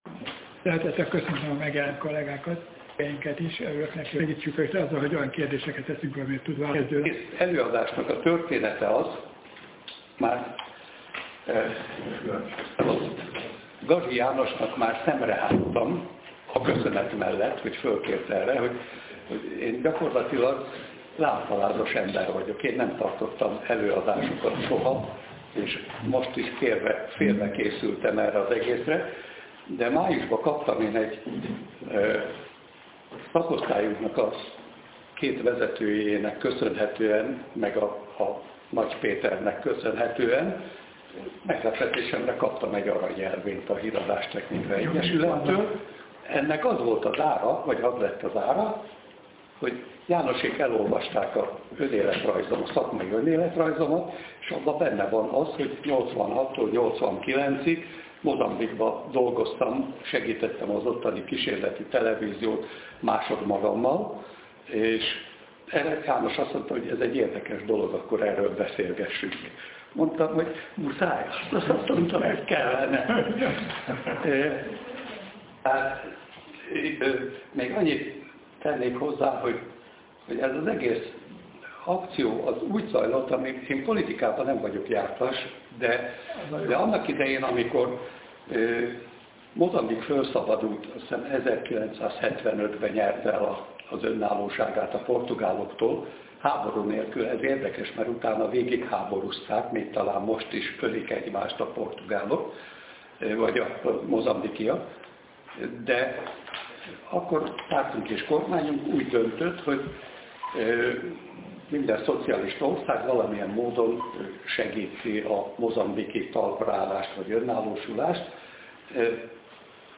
A HTE Szenior szakosztály szervezésében előadás hangzik el, melynek témája:
Előadás után kérdések, beszélgetés.